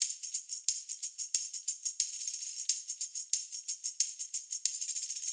Index of /musicradar/sampled-funk-soul-samples/90bpm/Beats
SSF_TambProc2_90-02.wav